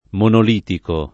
monolitico [ monol & tiko ] agg.; pl. m. -ci